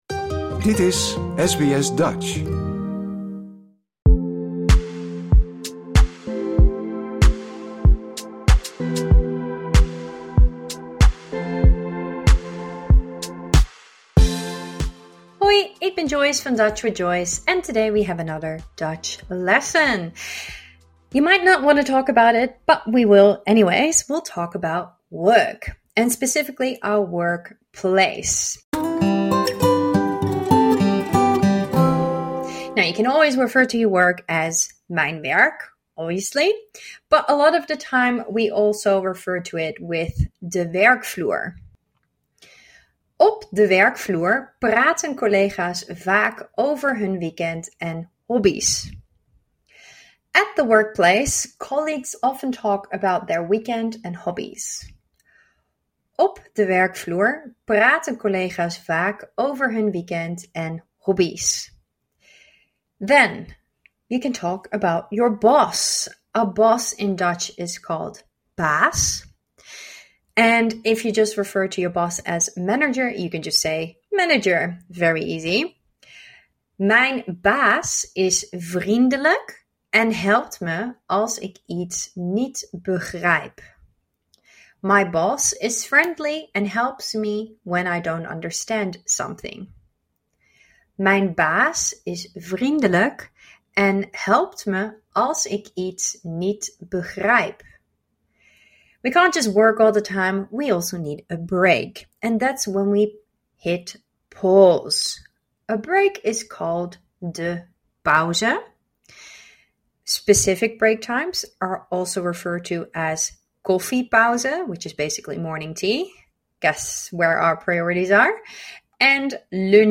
Words and sentences from lesson 83: the workplace